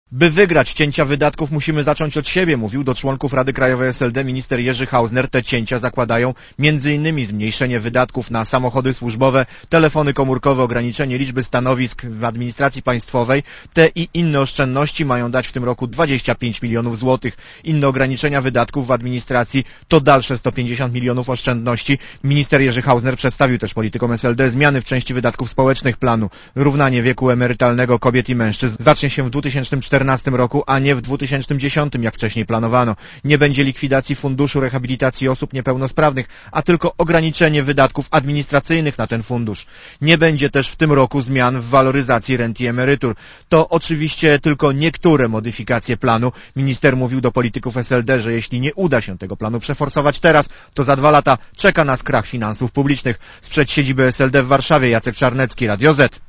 Katastrofy uda się uniknąć dzięki konsekwentnej realizacji planu wicepremiera Hausnera. Wicepremier przemawiał na posiedzeniu Rady Krajowej, która obraduje w Warszawie.